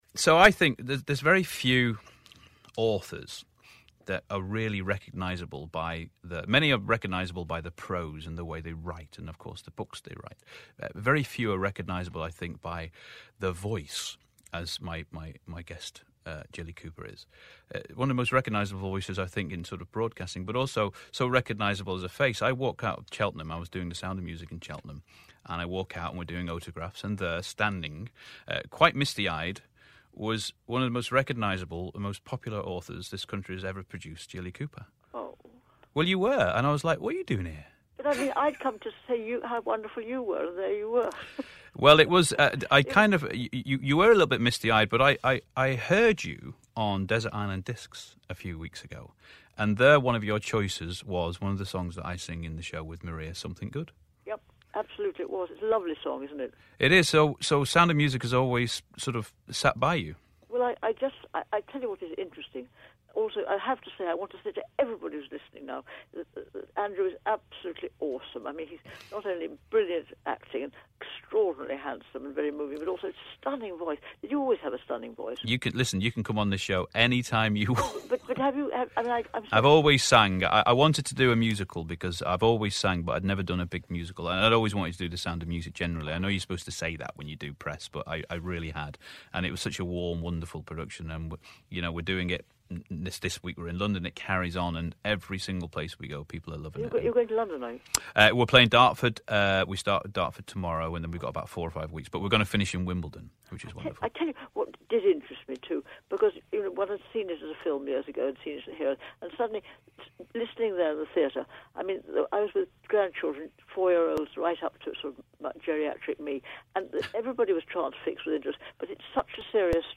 Listen again to Andrew Lancel chatting to author Jilly Cooper on Late Night City on Radio City 2 & Radio City Talk